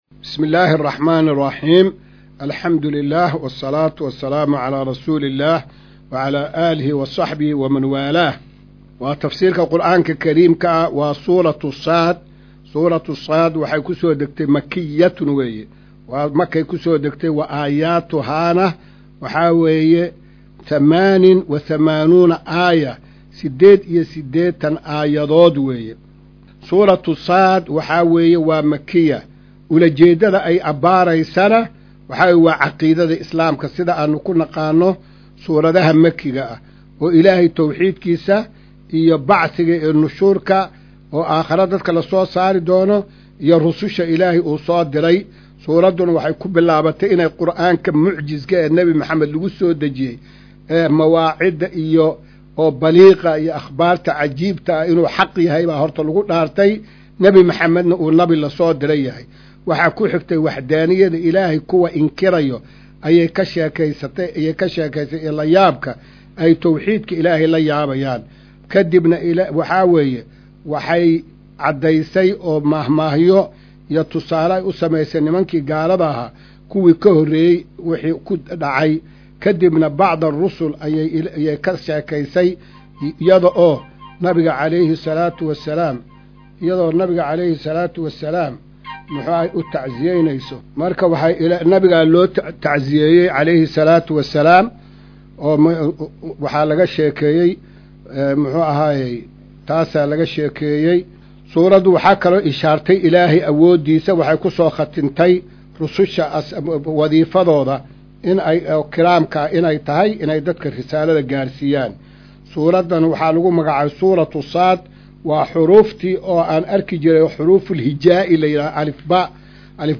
Maqal:- Casharka Tafsiirka Qur’aanka Idaacadda Himilo “Darsiga 215aad”